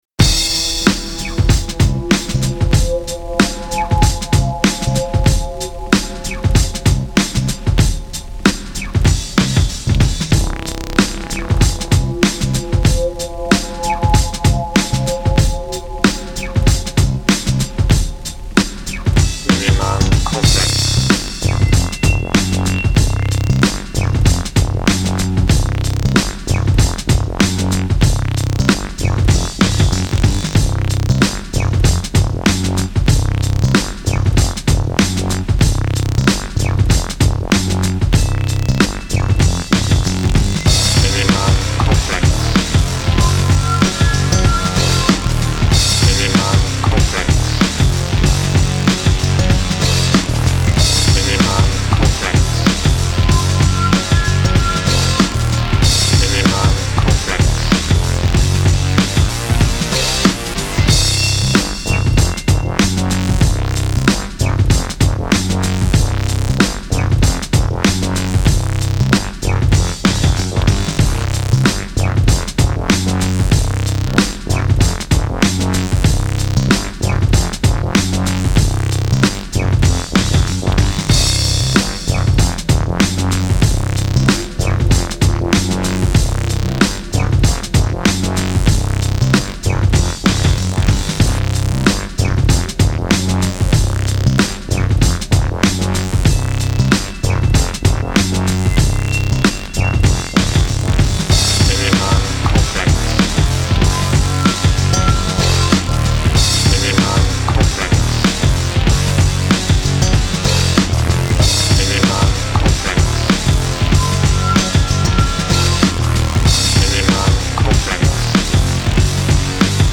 ELECTRONIC & SYNTHETIC ROCK MUSIC SINCE 1997